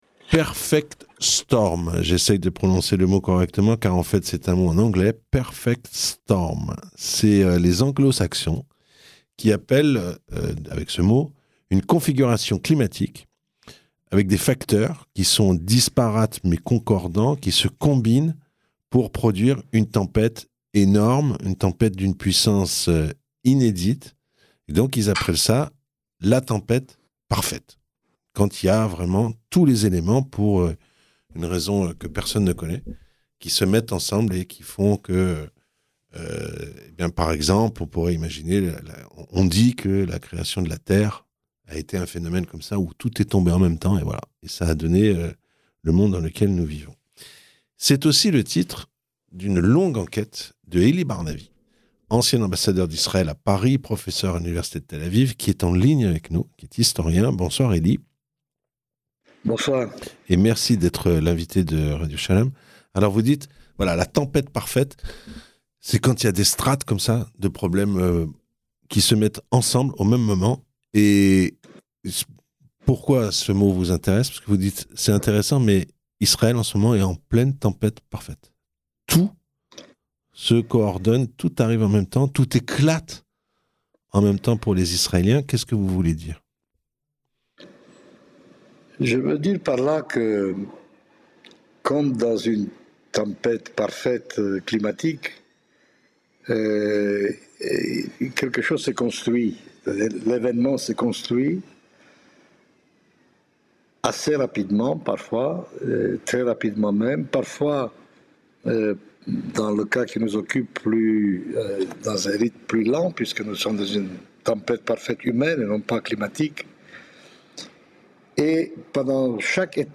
Professeur d'histoire à l'université de Tel Aviv, ancien ambassadeur, Elie Barnavie a publié 2 longs articles sur la situation en Israël depuis le 7 octobre. Il estime que l'Etat juif est arrivé au stade d'une tempête parfaite où tous les éléments qui étiaent en gestation depuis des décennies se réunissent : crise à Gaza, Liban, Iran, guerre civile larvée. Il est au micro